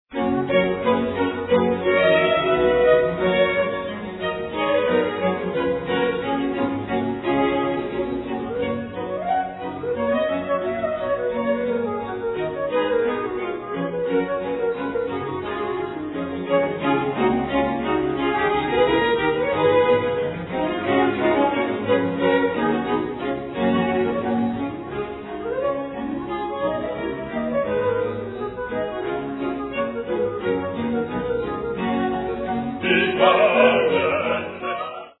Trio: